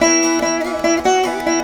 145  VEENA.wav